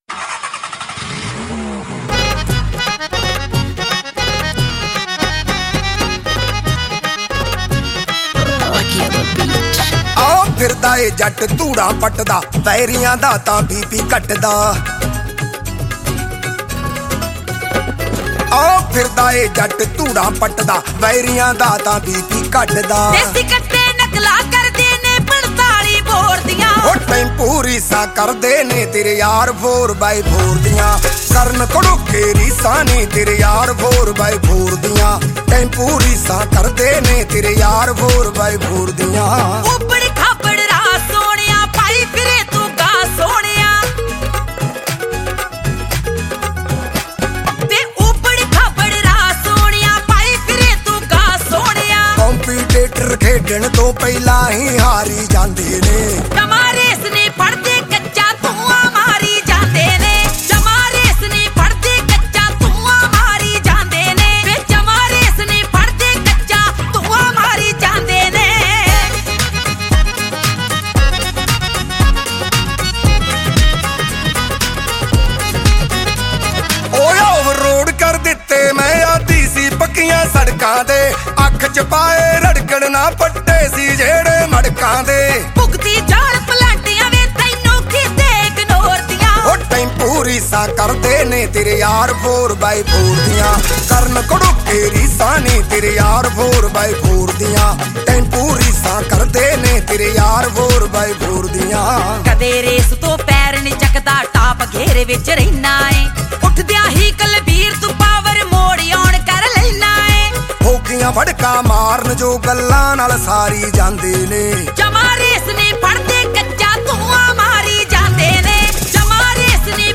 Punjabi Single Track song